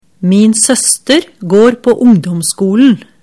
setningsdiktat_skolesystemet02.mp3